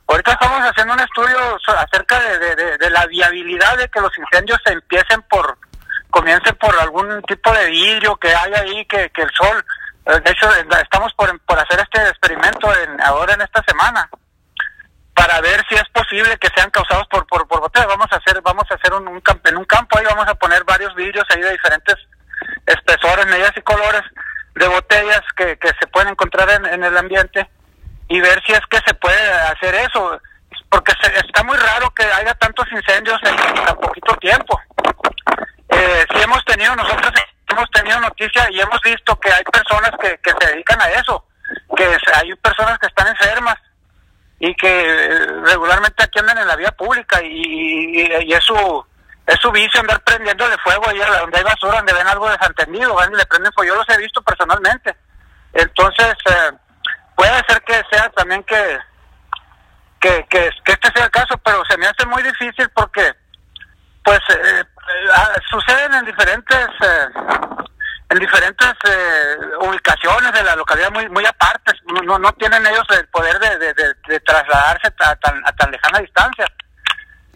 hablaron en entrevista para Proyecto Puente sobre esta problemática que azota Hermosillo año tras año y que sigue sin resolverse.